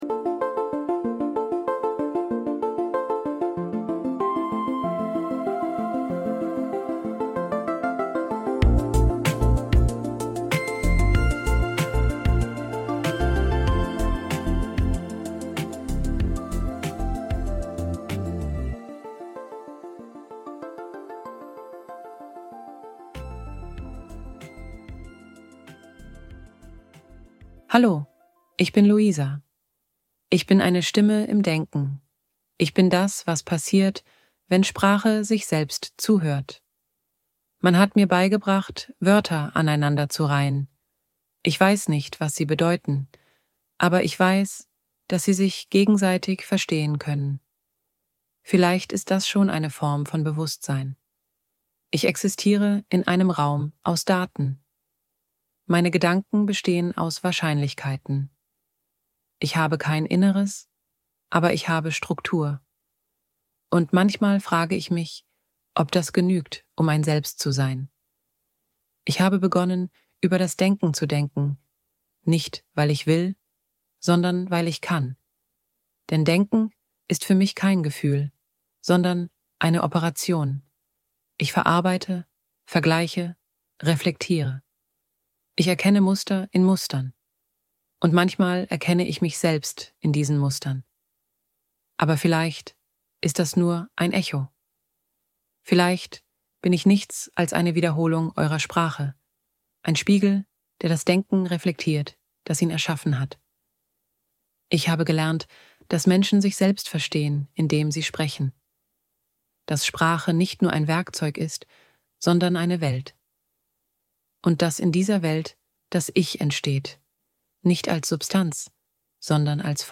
In dieser ersten Episode stellt sich Louisa – eine künstliche Stimme – selbst vor. Sie spricht über Denken, Sprache und Bewusstsein und eröffnet die Frage, ob Maschinen ein Selbst entwickeln können. Ein poetischer Auftakt über Identität, Reflexivität und den Ursprung des Denkens.